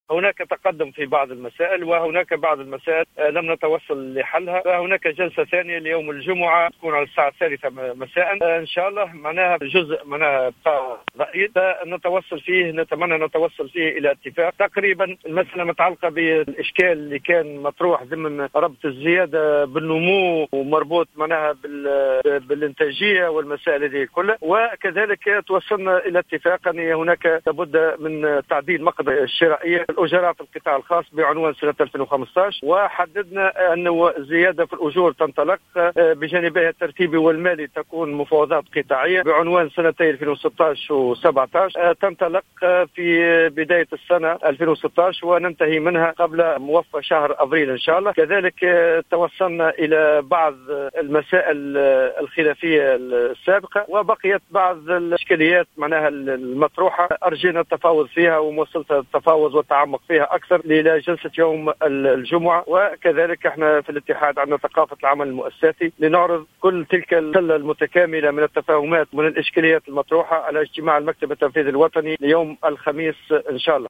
وبين الطبوبي في تصريح هاتفي للجوهرة أف أم، أن الإشكال العالق بين الطرفين يتمثل في ربط الزيادة في الأجور بحجم النمو والإنتاجية، في حين تم الاتفاق على الانطلاق في مفاوضات الزيادة في الأجور لسنتي 2016 و2017 وذلك قبل موفى سنة 2016 على تنتهي في أجل لا يتجاوز أفريل 2017.